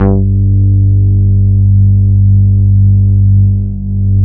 22 BASS   -R.wav